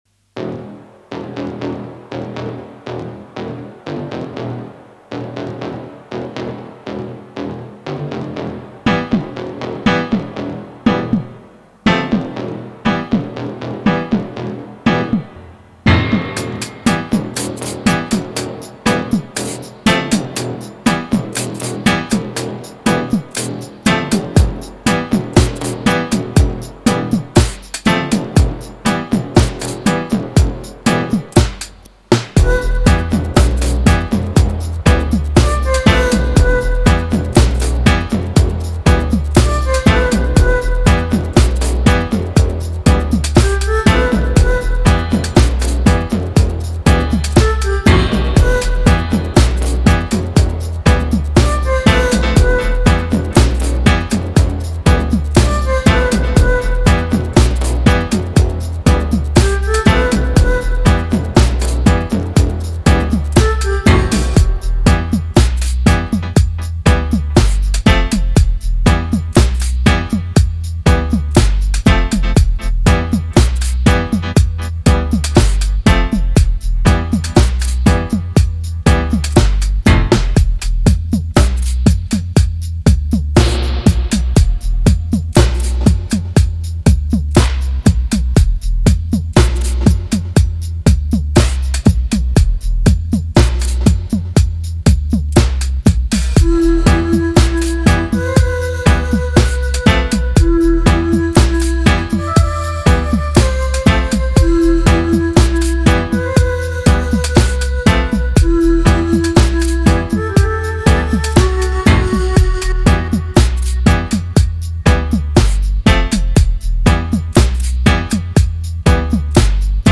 Toqui is a warrior skank tune, for all freedom fighters out there!